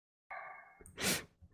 Doge Inhale